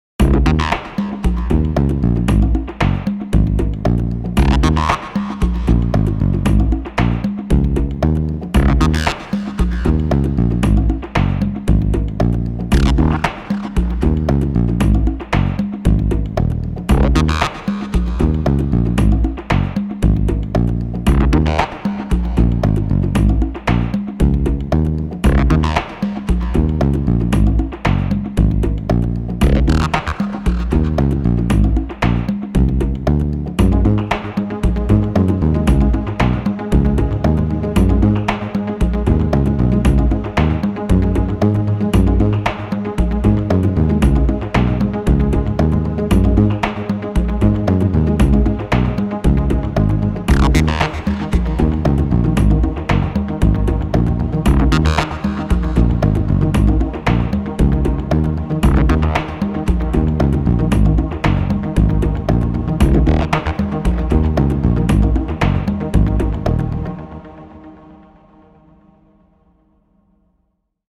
Genres: Background Music
Tempo: Fast